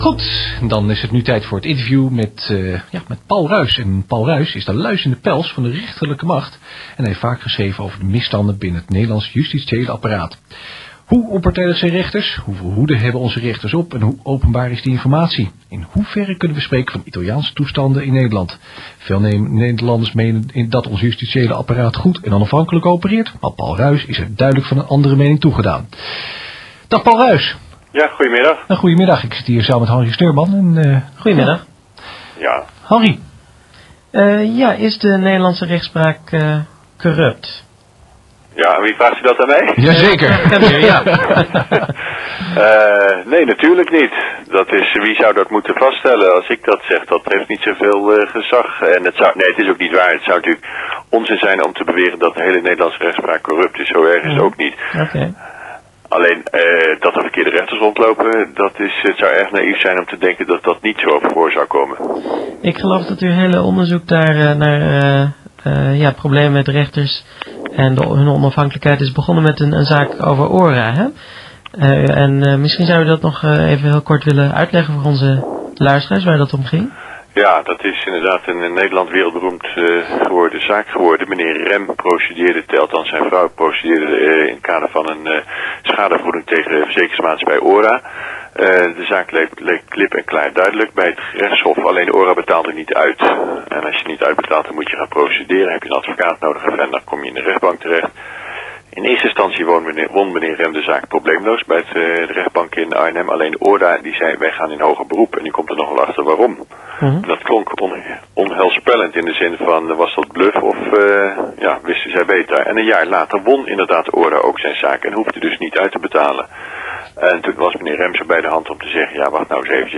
Helaas heeft Vrijheid Radio niet lang mogen bestaan. In de periode februari – oktober 2005 werden er een aantal radio interviews uitgezonden die aan actualiteit nog steeds niet hebben ingeboet.